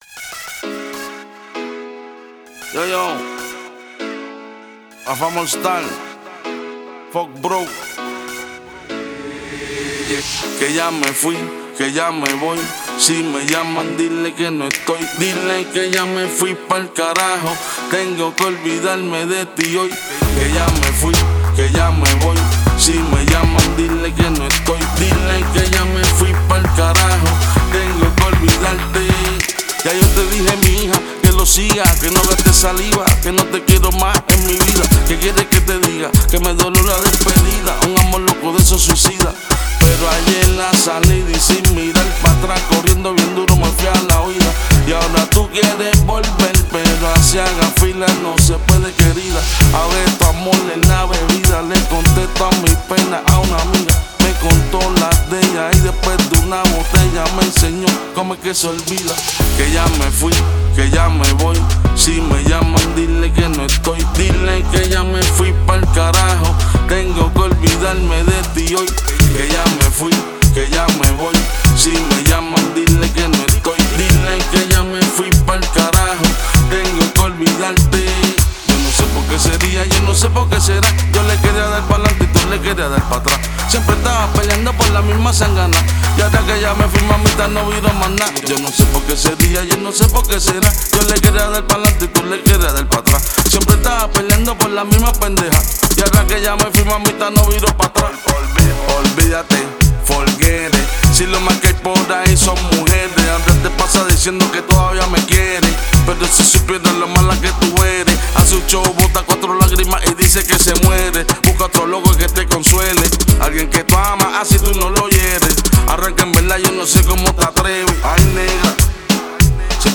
Genre: Punjabi